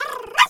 dog_2_small_bark_03.wav